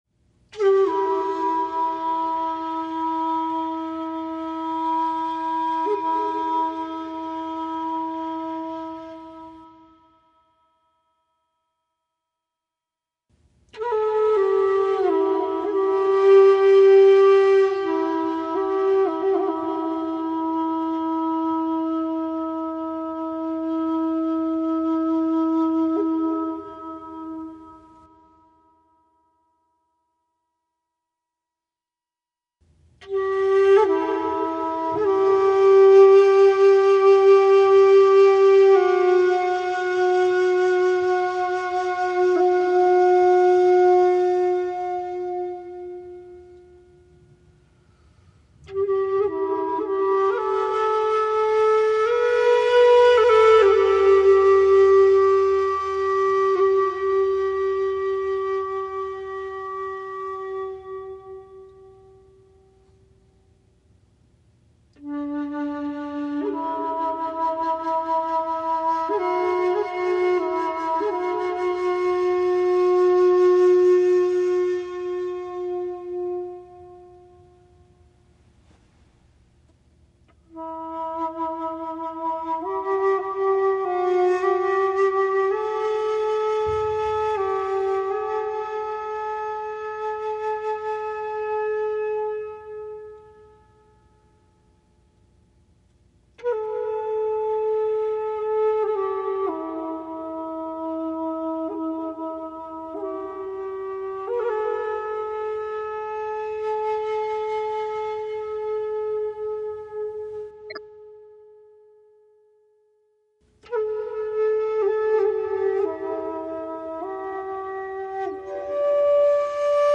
音乐使我放松，使人有飘起来的感觉。
古/萧竹笛的原始之声，抒展/深远/悠扬，飘逸若仙，高而不亢，低而不壅，连绵不断，显示古木带来春天！